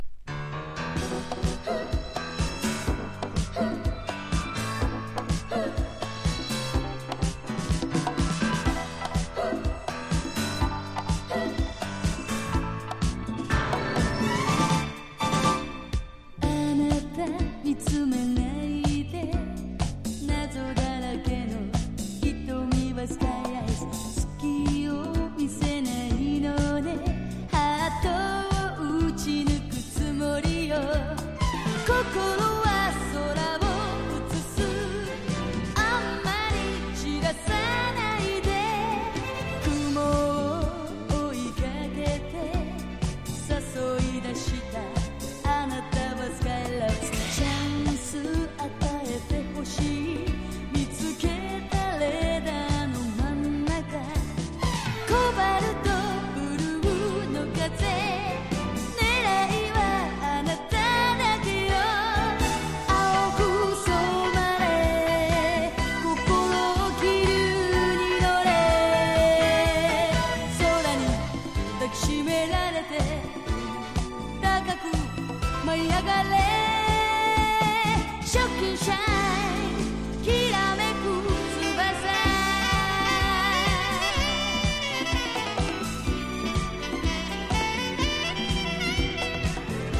当時最先端だったPOLY MOOG、OBERHEIM 8 VOICEなどを多用したサウンドにも要注目!!
FOLK# POP# CITY POP / AOR# 和モノ